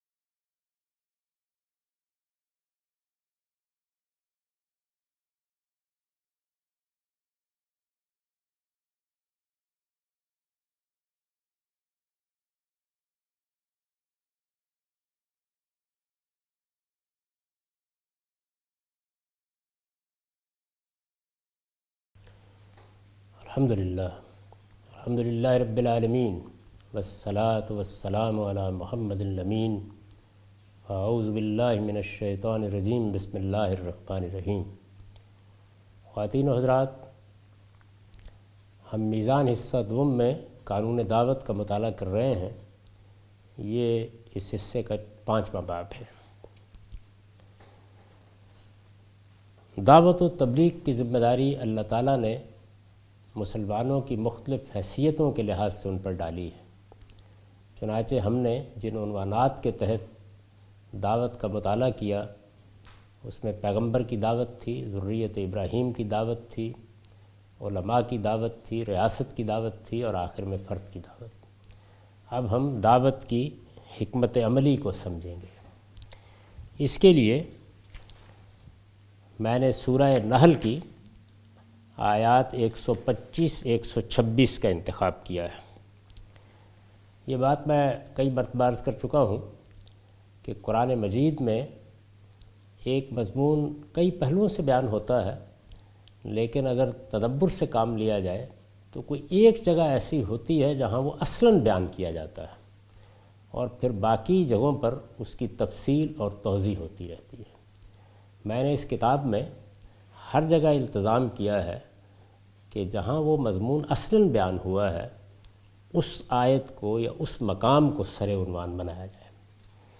A comprehensive course taught by Javed Ahmed Ghamidi on his book Meezan. In this lecture series he will teach The Shari'ah of Preaching. This lecture contains introduction to the topic 'Strategy of preaching'.